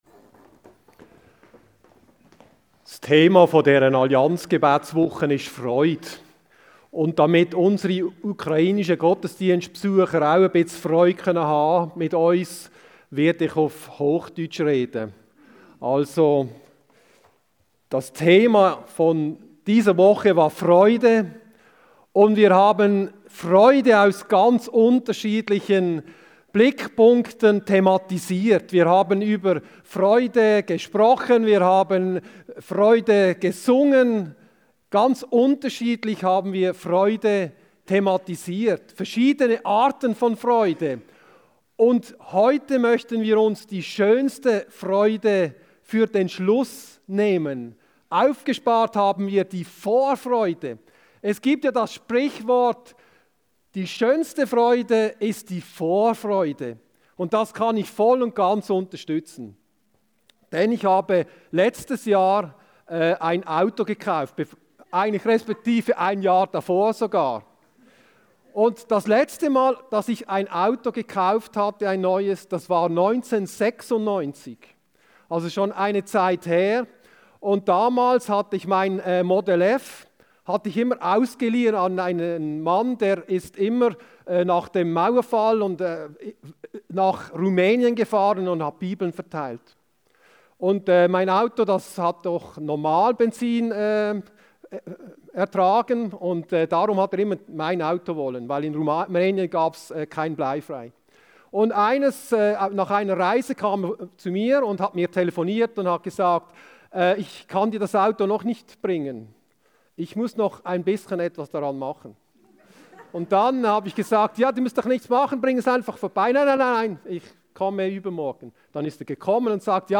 FREUDE! Allianz Gottesdienst